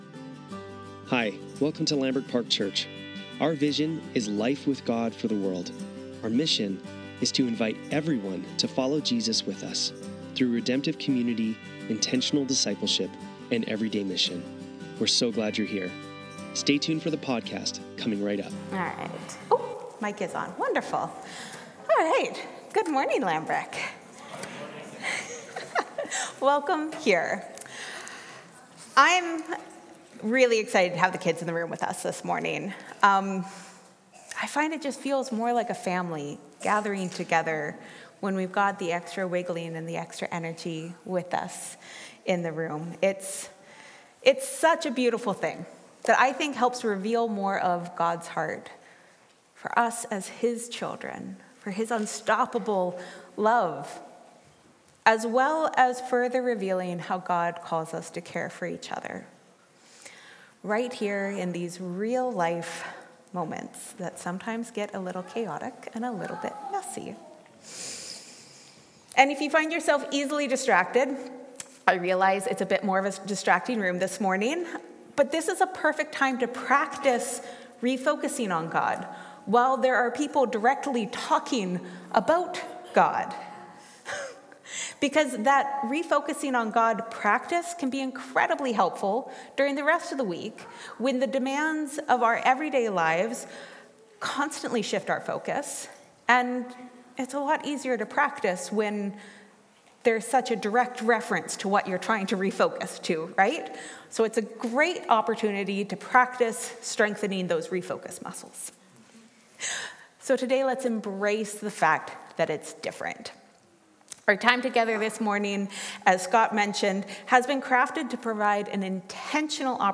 Lambrick Sermons | Lambrick Park Church
Sunday Service - September 21, 2025